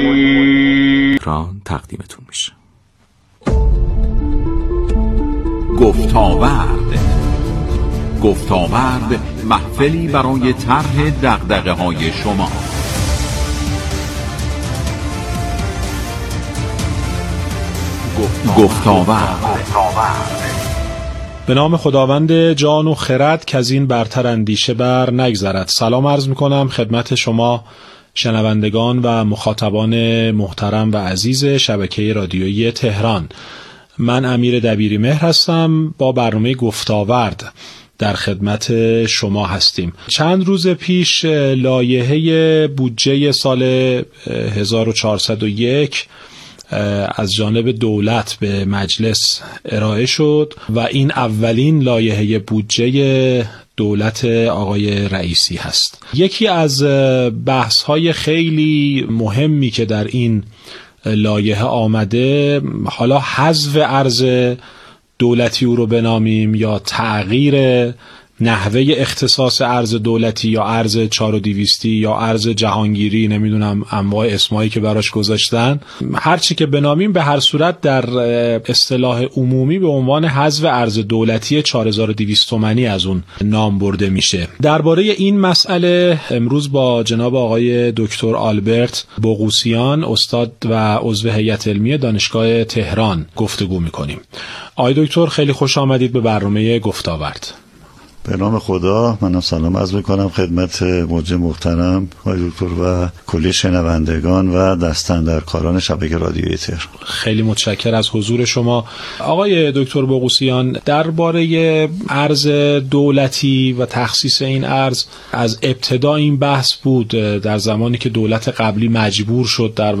اسلایدر / مهمترین مطالببرنامه رادیوییمصاحبه و گفتگو